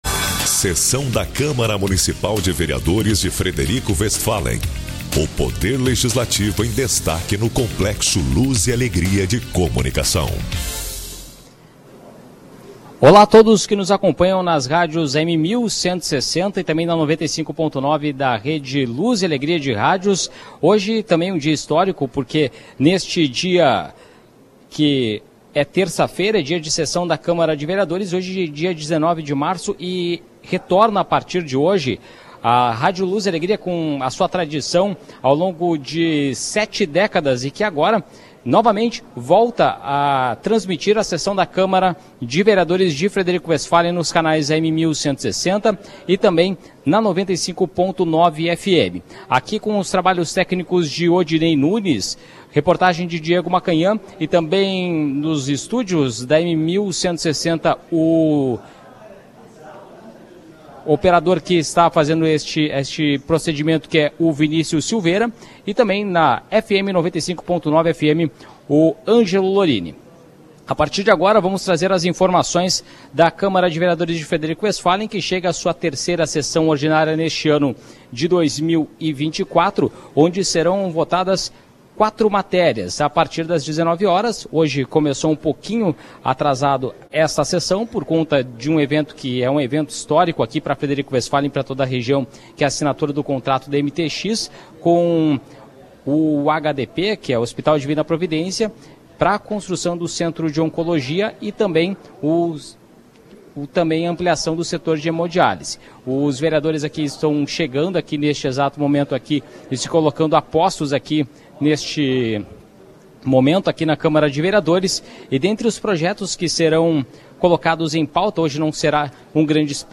Sessão Ordinária do dia 19 de março de 2024